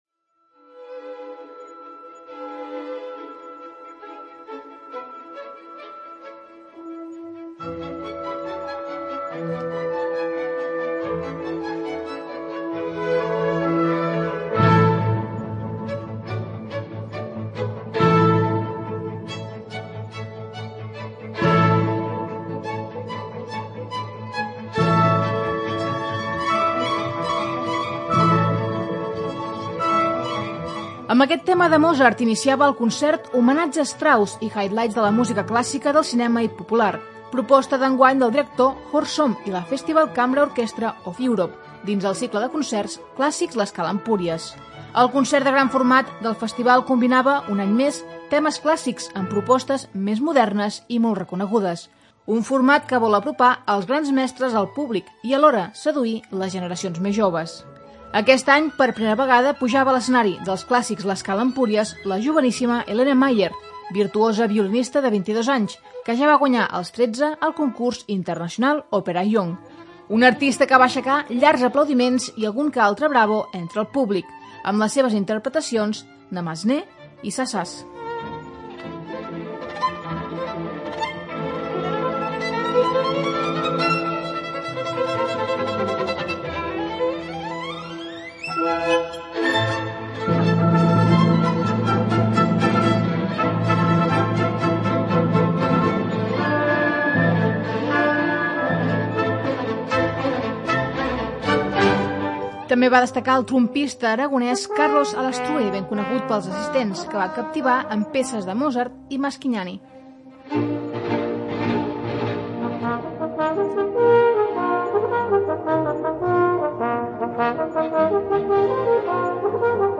El públic assistent va respondre amb entusiasme i es va deixar portar fins al gran homenatge a Johann Strauss fill, amb el seu inconfusible vals del Danubi blau.